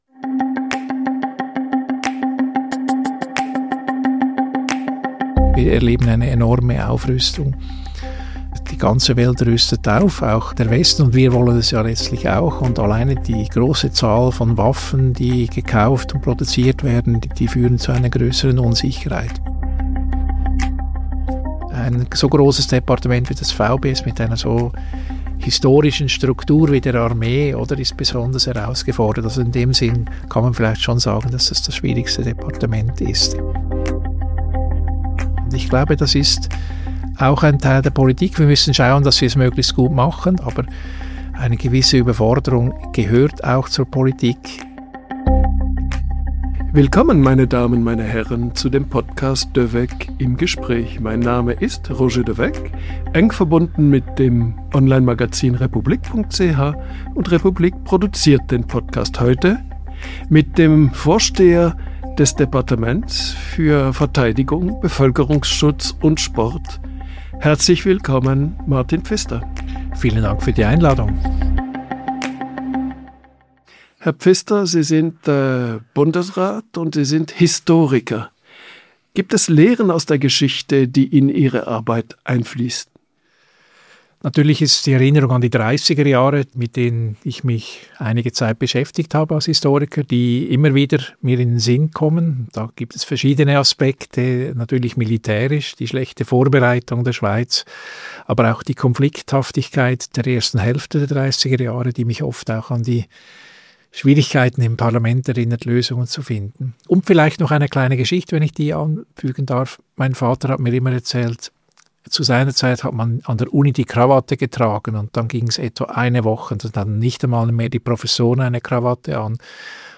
Was muss geschehen, damit die Schweiz sich verteidigen kann? Publizist Roger de Weck spricht im Podcast mit Bundesrat Martin Pfister über den Zustand der Armee in unsicheren Zeiten.